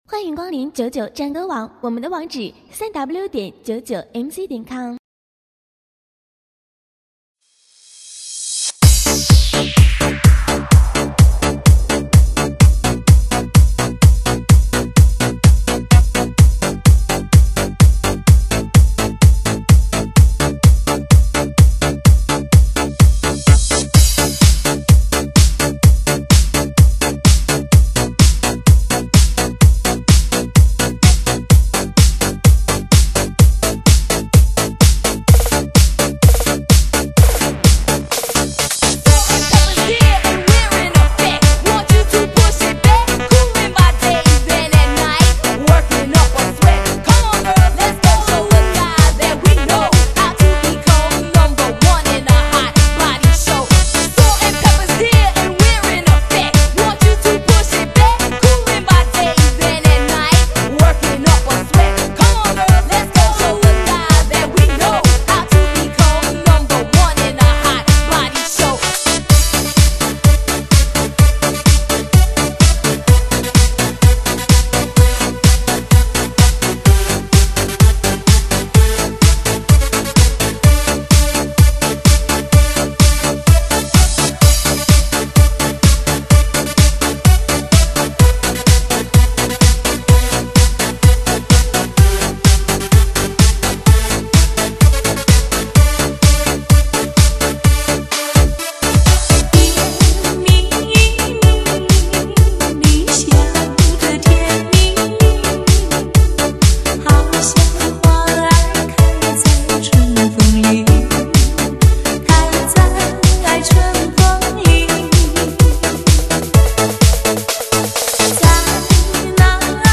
Mc伴奏